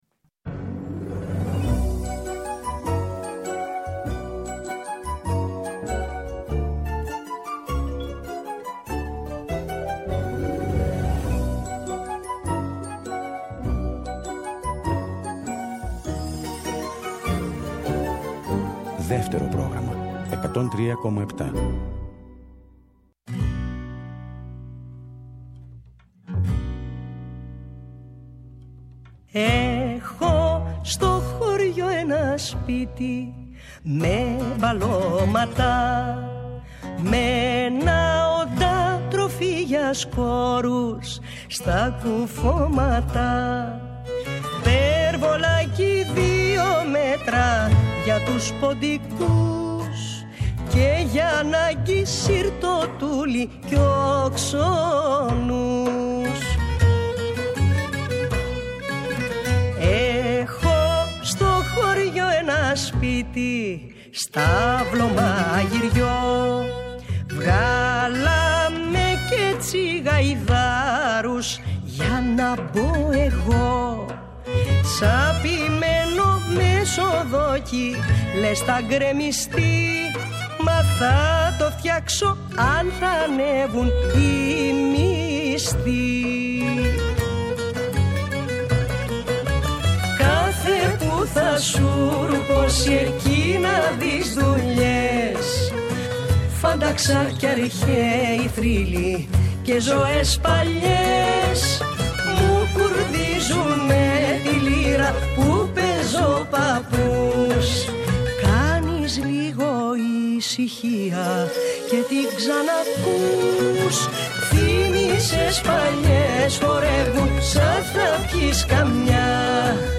Τί καλύτερο για το Σαββατόβραδο από μια εκπομπή με τραγούδια που αγαπήσαμε;